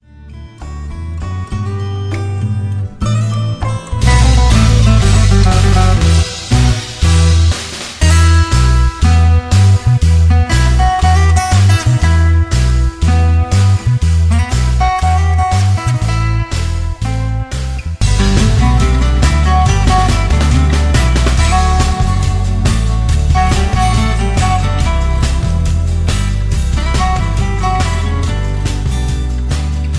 eleven demo songs